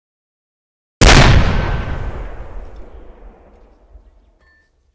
mortar_fire.ogg